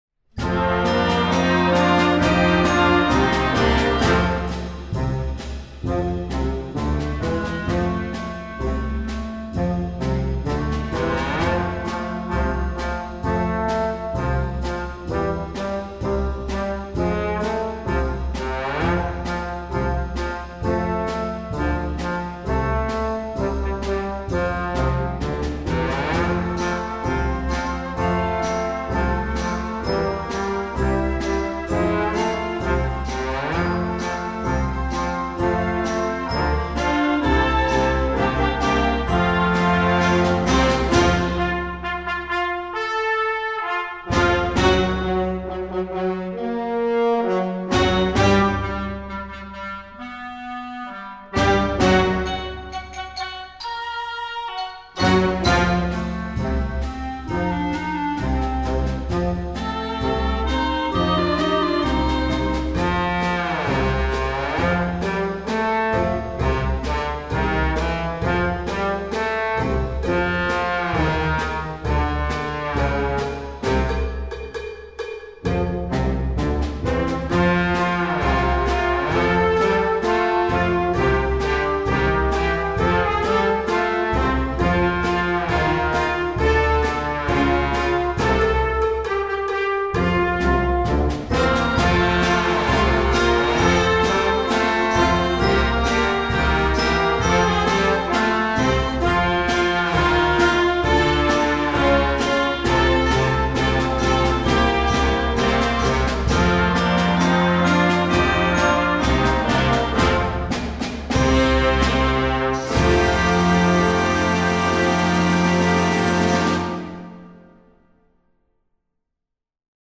Voicing: Trombone Section w/ Band